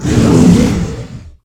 hurt3.ogg